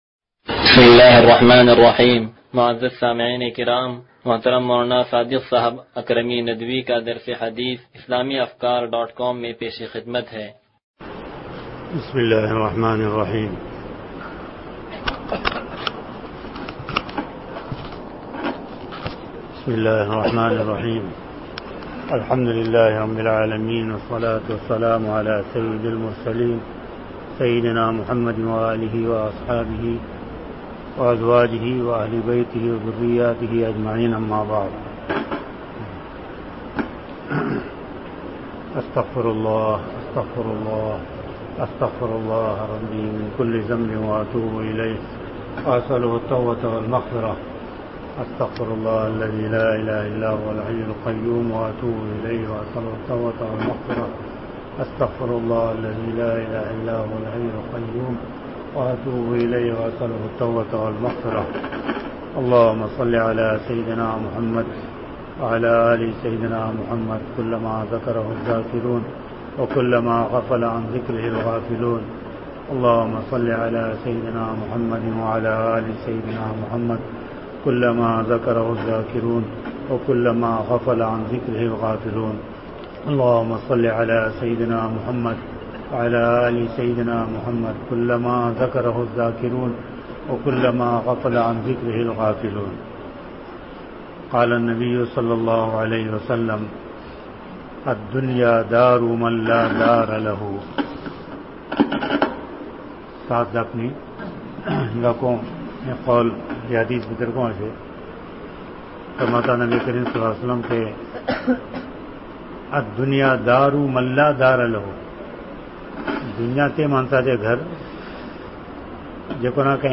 درس حدیث نمبر 0192